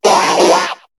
Cri de Psykokwak dans Pokémon HOME.